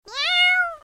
دانلود آهنگ گربه بامزه از افکت صوتی انسان و موجودات زنده
دانلود صدای گربه بامزه از ساعد نیوز با لینک مستقیم و کیفیت بالا
جلوه های صوتی